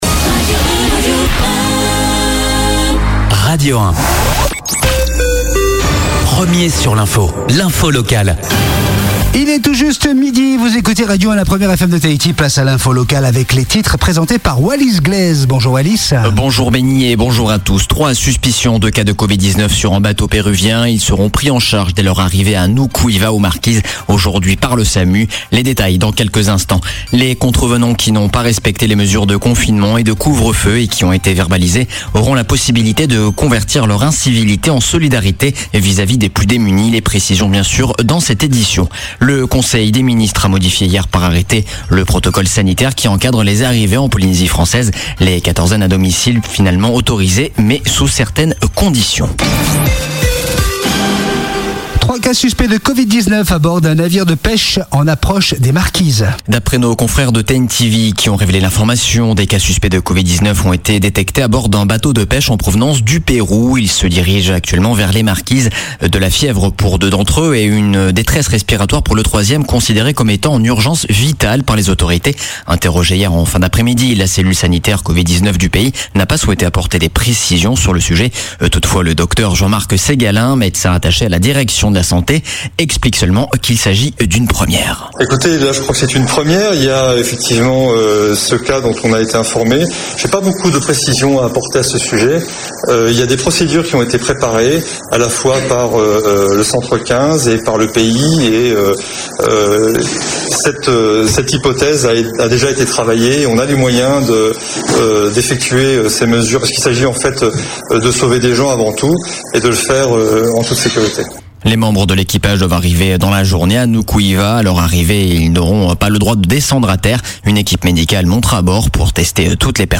Journal de 12:00, le 19/05/2020